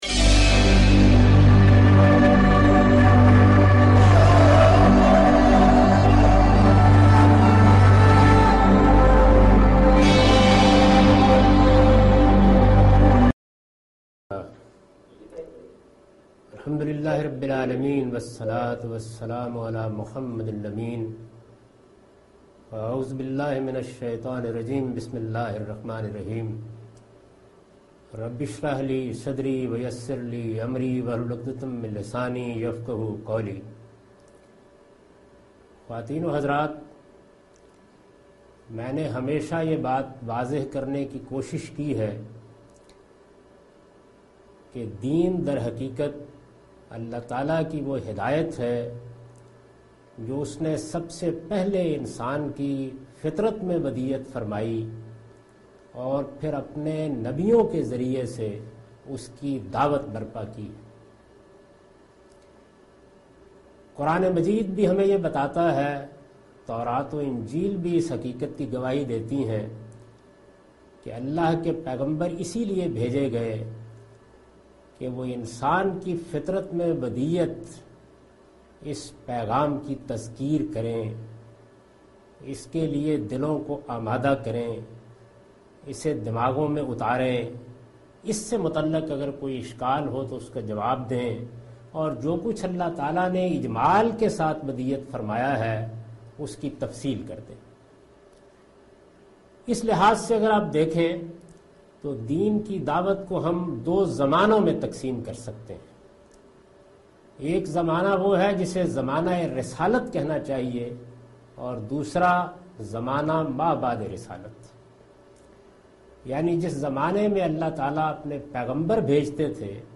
Javed Ahmad Ghamidi's key note address to Al-Mawrid's global Da'wah conference on 24th March.